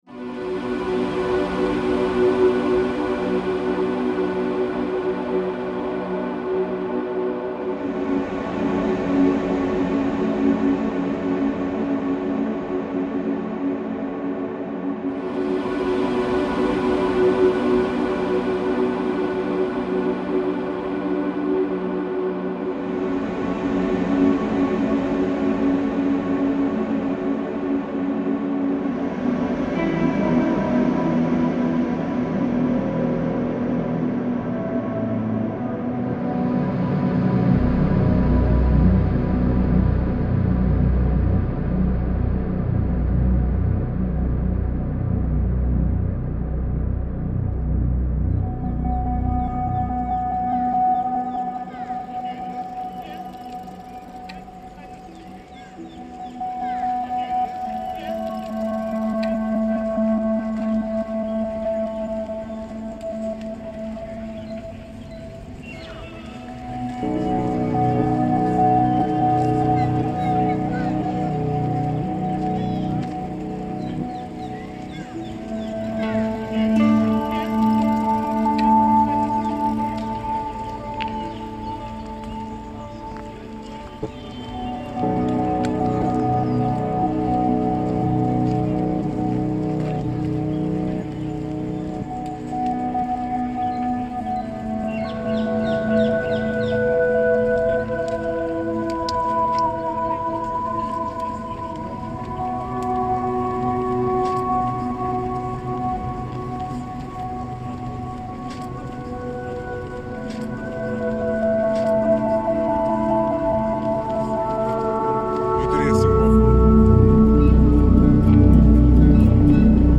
Roman boating lake reimagined